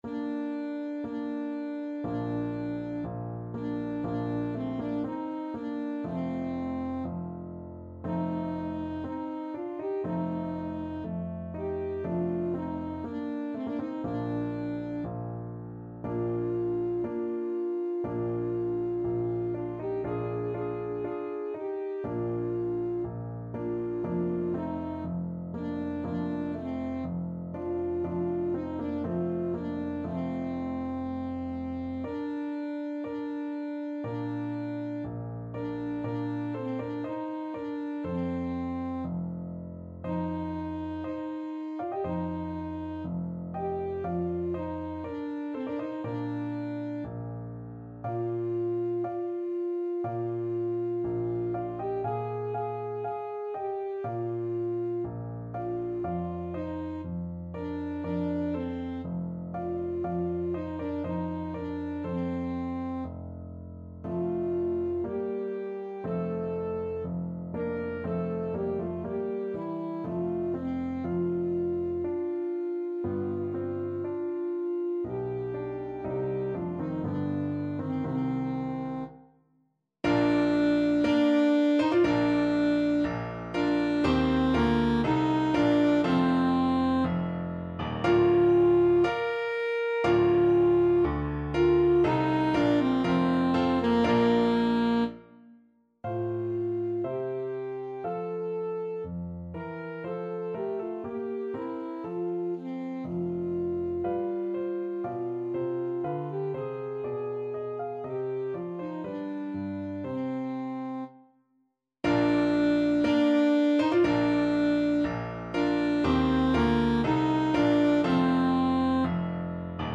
Alto Saxophone
Bb major (Sounding Pitch) G major (Alto Saxophone in Eb) (View more Bb major Music for Saxophone )
4/4 (View more 4/4 Music)
Slow =c.60
Bb4-Bb5
Classical (View more Classical Saxophone Music)
handel_saul_dead_march_ASAX.mp3